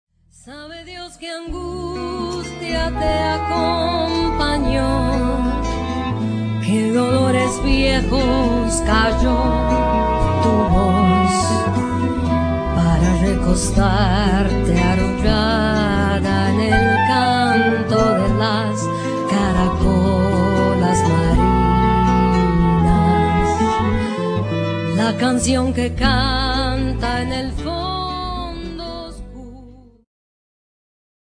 ジャンル Progressive
アコースティック
ローマ出身のアコースティック楽器と女性ヴォーカルによるグループ。